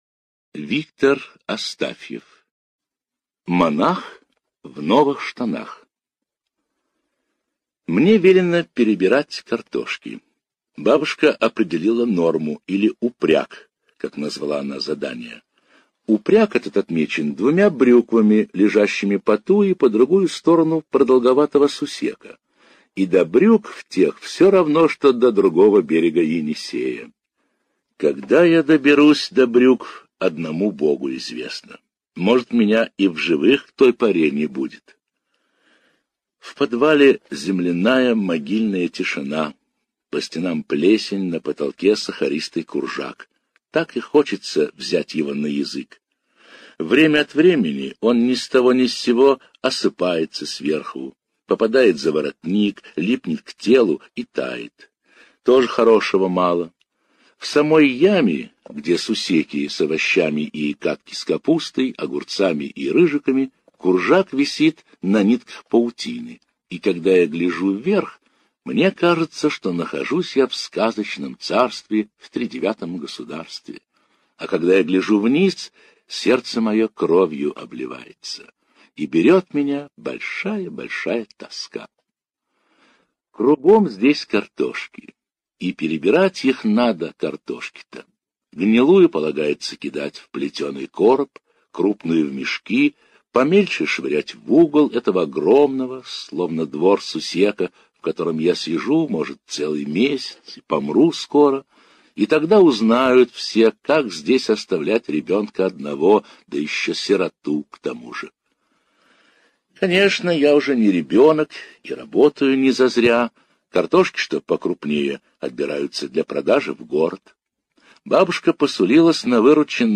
Слушать Монах в новых штанах - аудио рассказ Астафьева В.П. Рассказ про доброго и доверчивого мальчика Витю, о любви к ближним, дружбе.